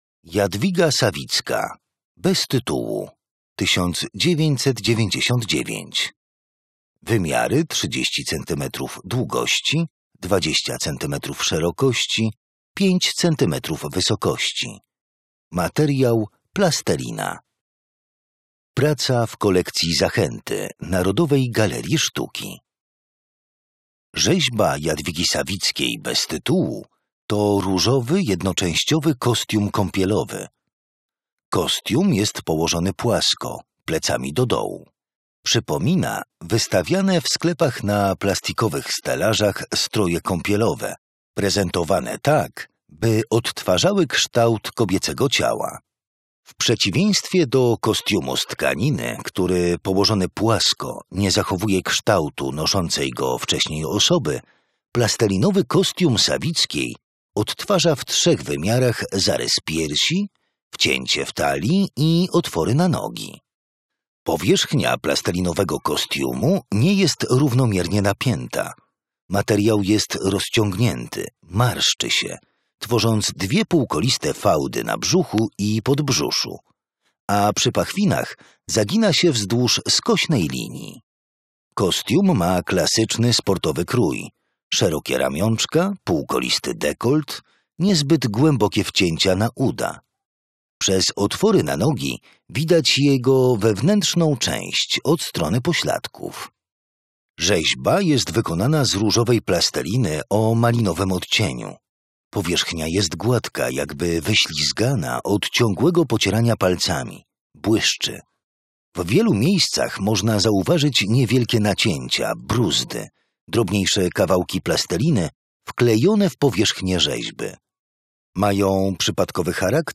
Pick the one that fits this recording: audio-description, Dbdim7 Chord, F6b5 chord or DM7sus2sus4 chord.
audio-description